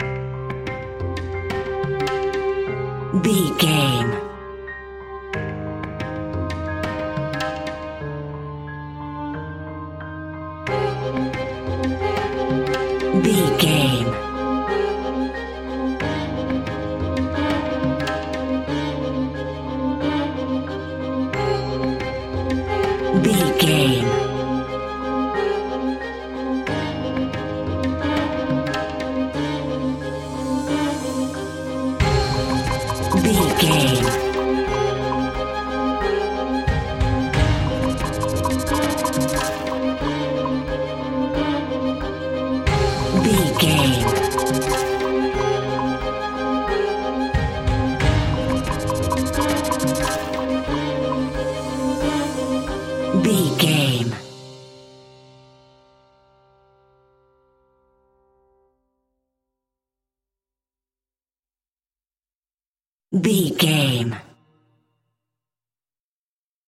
Aeolian/Minor
ominous
dark
eerie
piano
percussion
synthesizer
horror music
Horror Pads